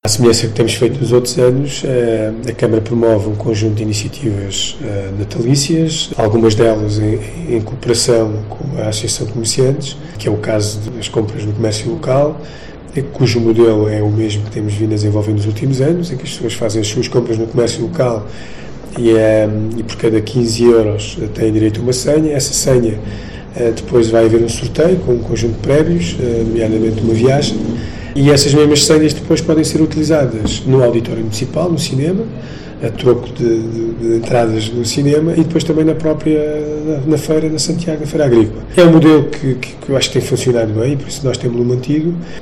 Declarações do Presidente da Câmara Municipal de Santiago do Cacém, Álvaro Beijinha